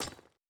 Impact on Stones.wav